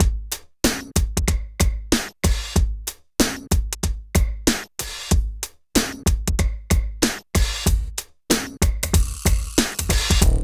87 DRUM LP-L.wav